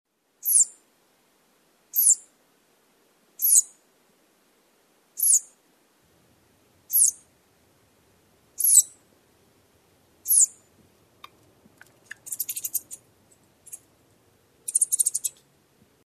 Southern Flying Squirrel
Voice
These squirrels produce vocalizations, including a high-pitched tseet and other chirping sounds. Vocalizations are sometimes above the frequency range of the human ear.
southern-flying-squirrel-call.mp3